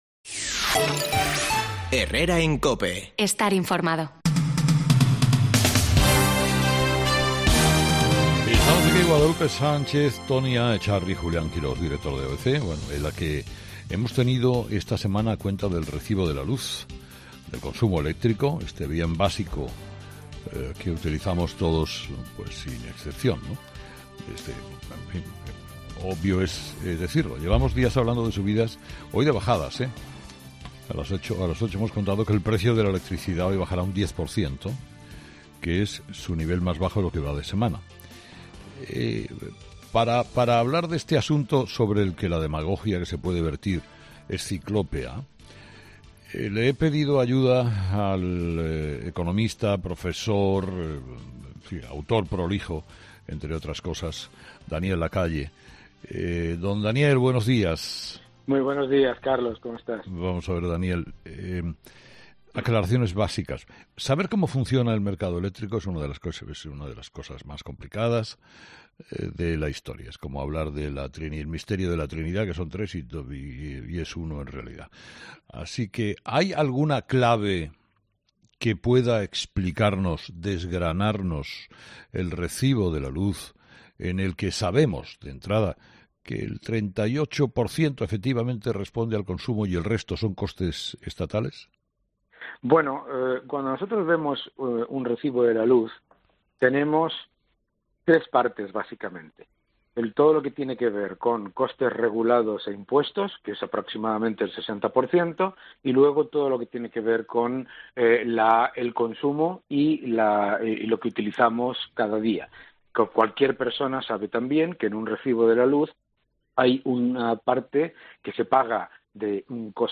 El economista Daniel Lacalle ha sido entrevistado este viernes en 'Herrera en COPE' para explicar cómo funciona el mercado eléctrico tras el encarecimiento del precio de la electricidad en plena ola de frío.